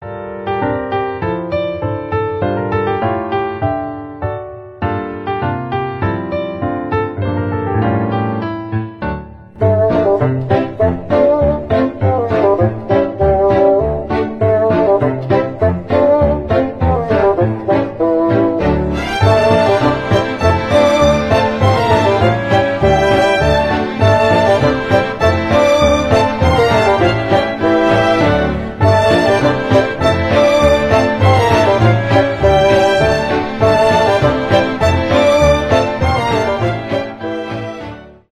без слов
инструментальные